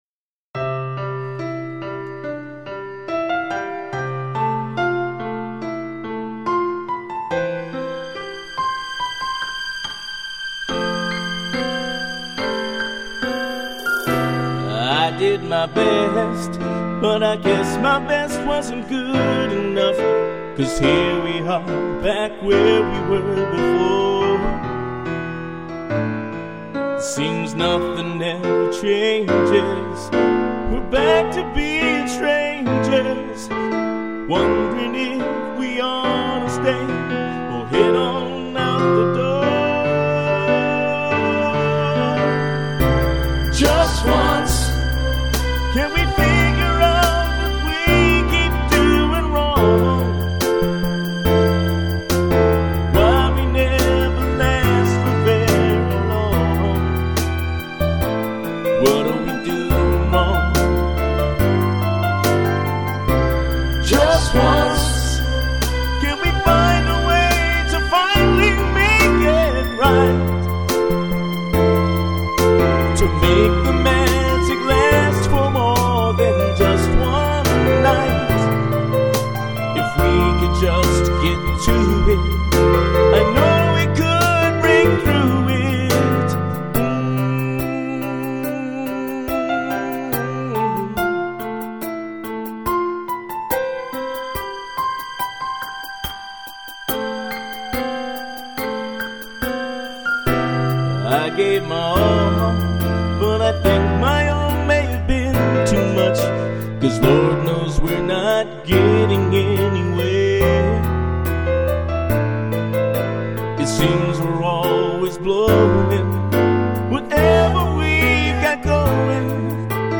Pittsburgh's premiere dance music band
vocals